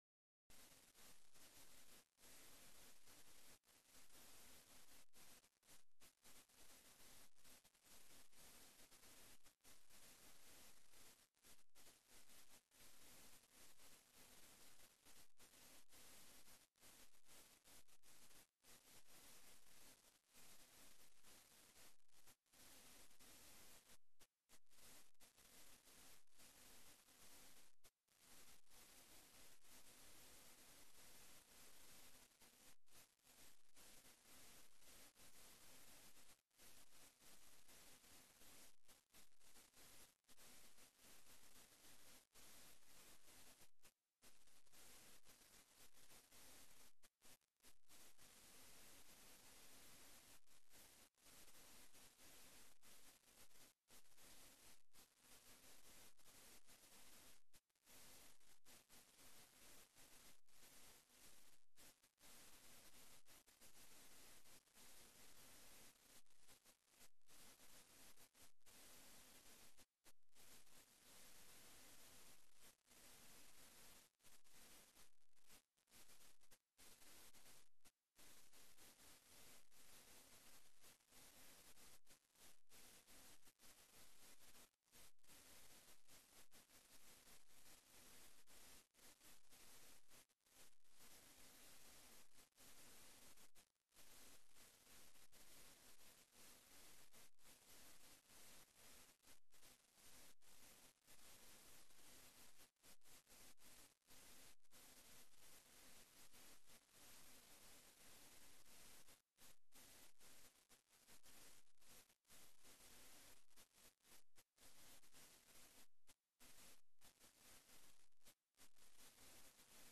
Acto homenaje a las 113 mujeres que han sido diputadas en el Parlamento de Canarias - 18:00